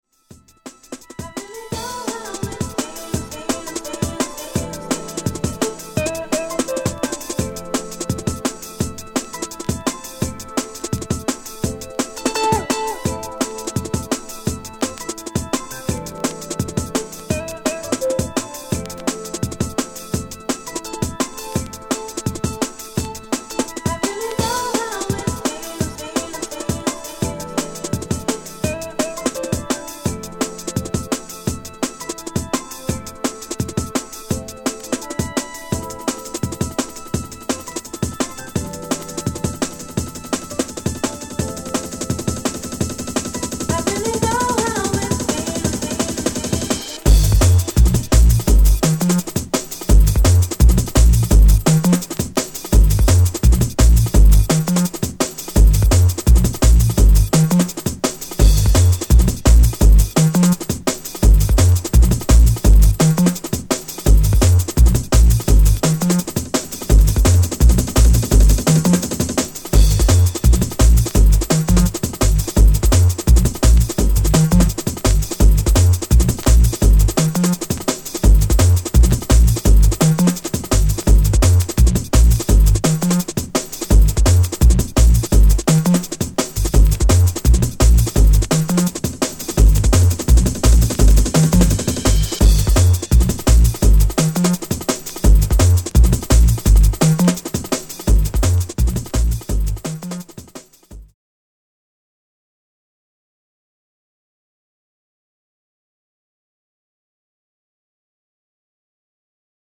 Jungle/Drum n Bass